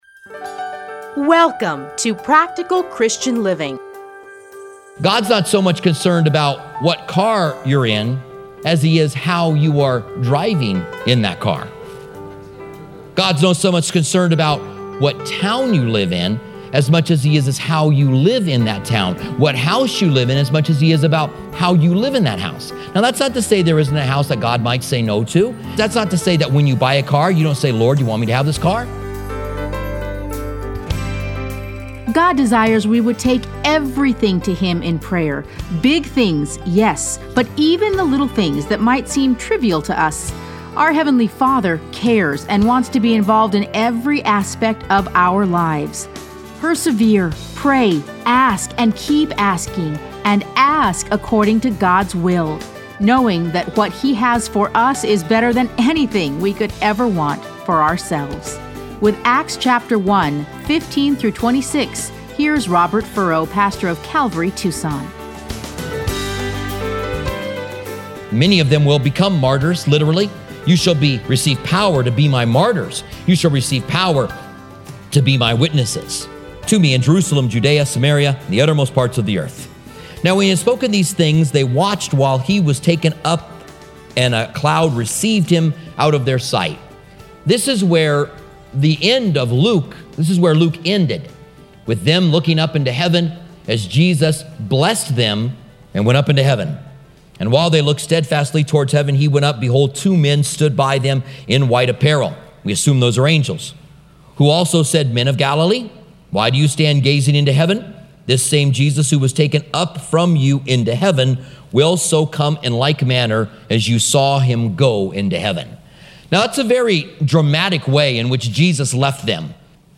Listen to a teaching from Acts 1:15-26.